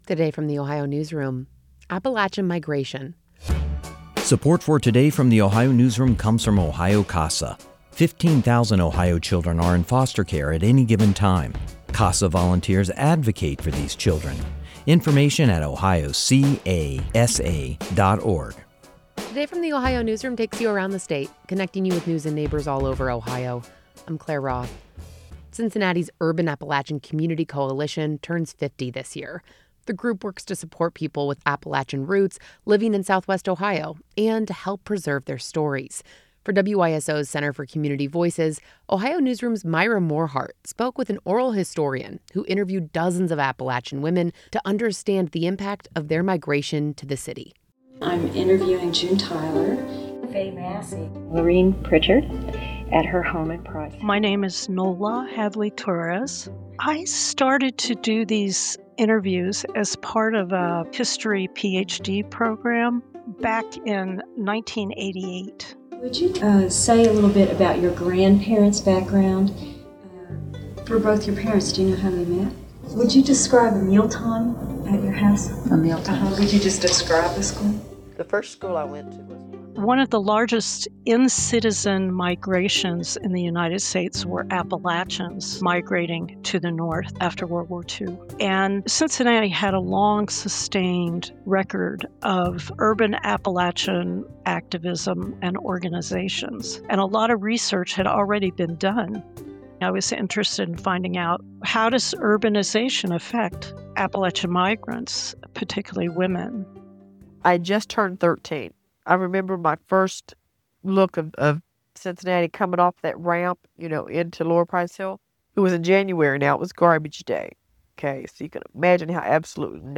Note: Archival audio provided by the Louie B. Nunn Center for Oral History, University of Kentucky Libraries from "Appalachia: Out-Migration Project: Urban Appalachian Women in Cincinnati, Ohio Oral History Project."